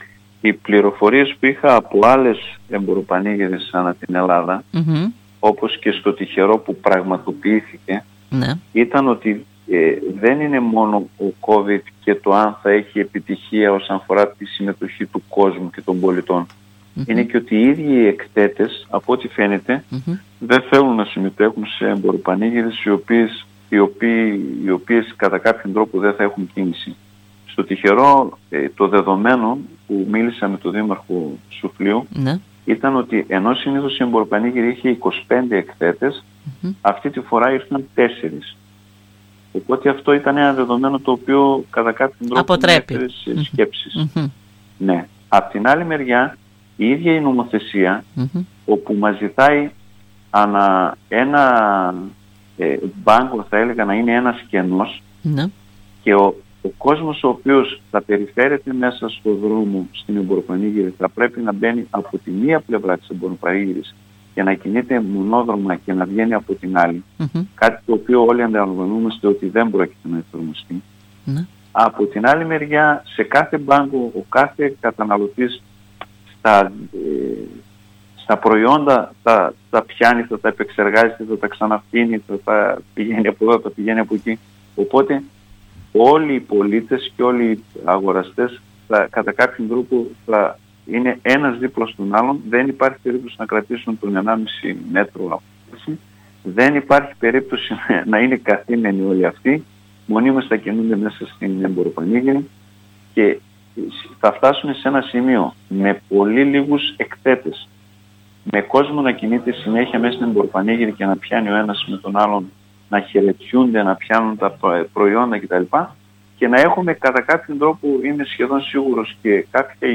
Ο δήμαρχος Διδυμοτείχου Ρωμύλος Χατζηγιάννογλου μιλώντας στην ΕΡΤ Ορεστιάδας ανέφερε ότι οι προϋποθέσεις και τα μέτρα που εφαρμόζονται λόγω της πανδημίας είναι τέτοια που είναι πολύ δύσκολο να τηρηθούν από εκθέτες και επισκέπτες.